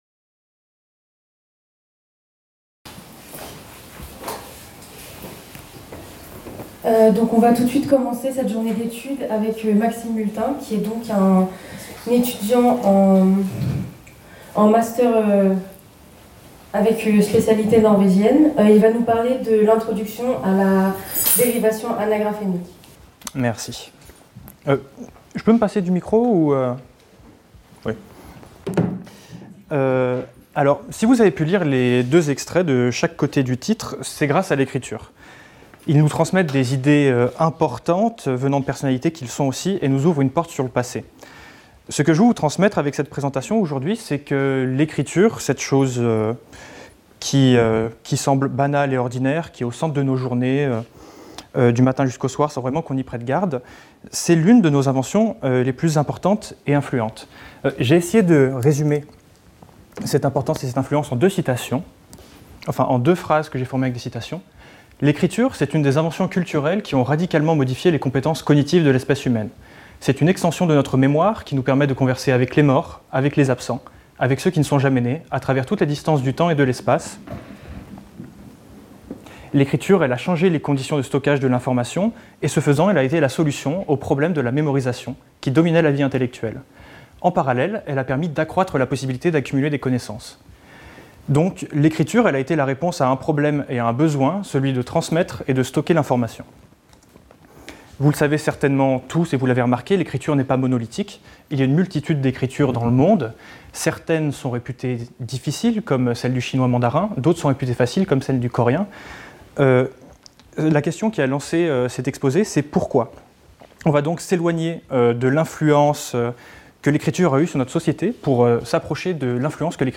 Journée d'étude : " Transmission : entre culture et linguistique"